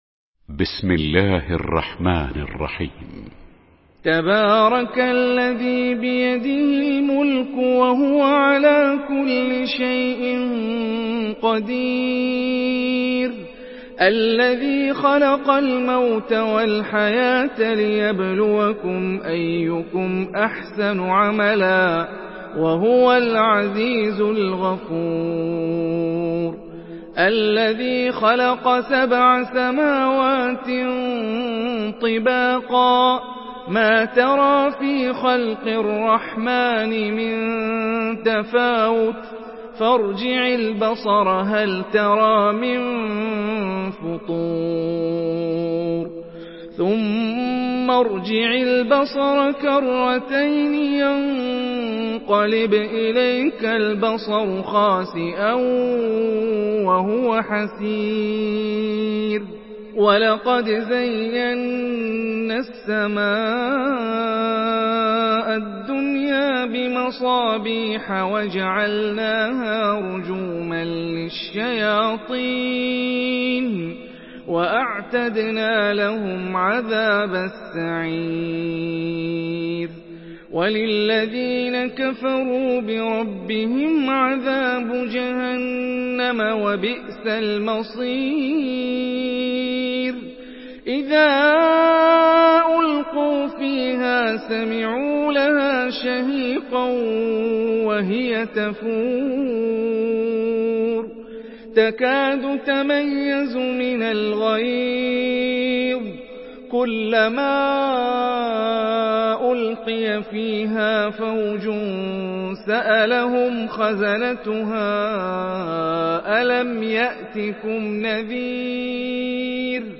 Surah Al-Mulk MP3 in the Voice of Hani Rifai in Hafs Narration
Murattal Hafs An Asim